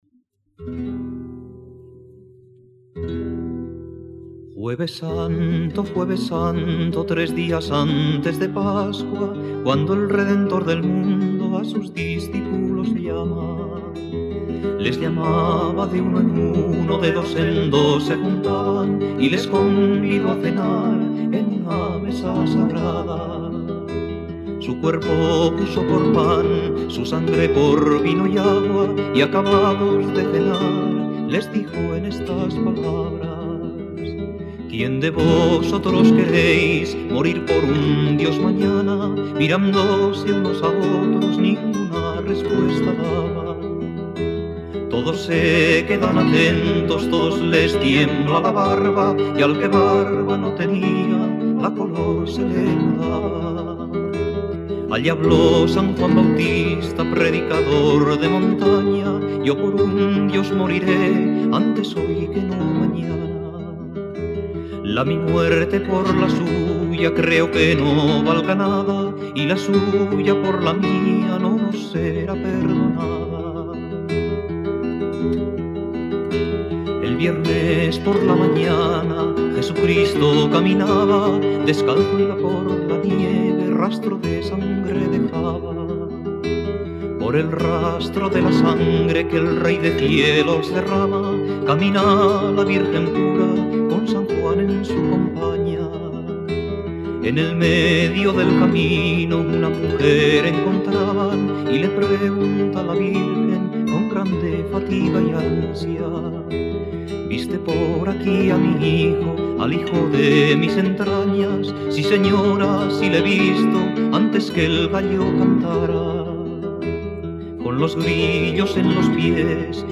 Voz y guitarra